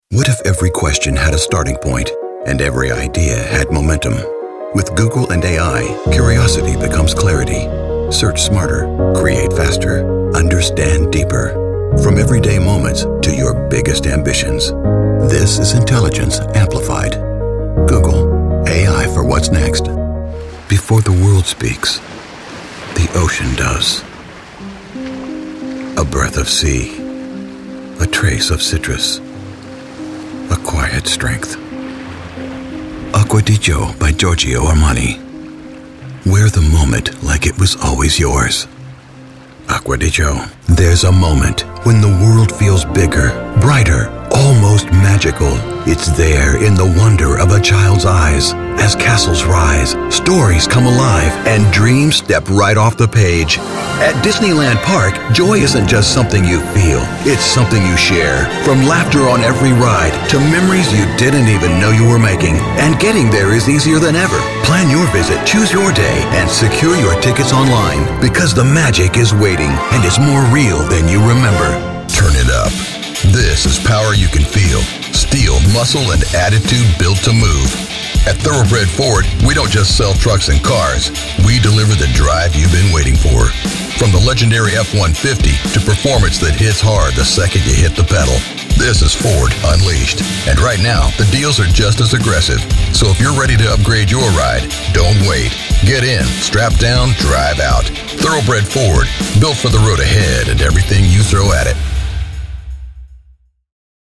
Never any Artificial Voices used, unlike other sites.
Adult (30-50) | Older Sound (50+)
Our voice over talent record in their professional studios, so you save money!